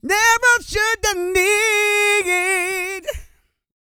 E-SING W 114.wav